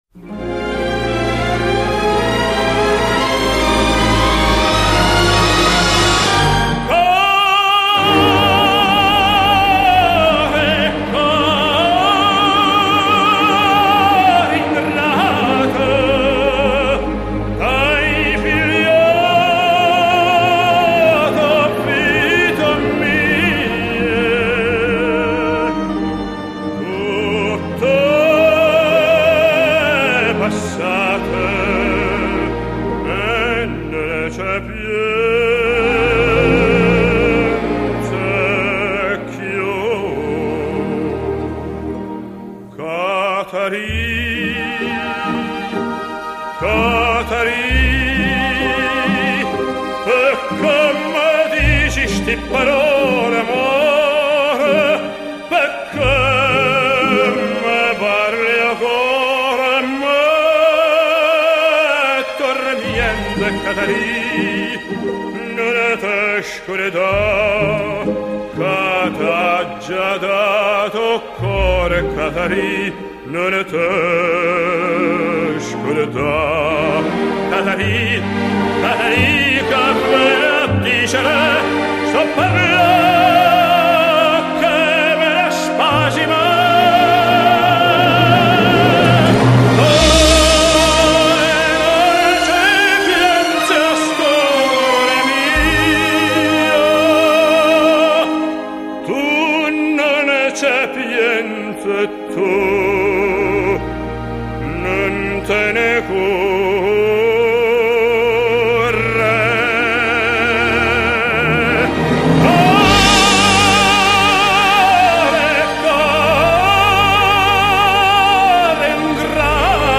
由于原录音是1962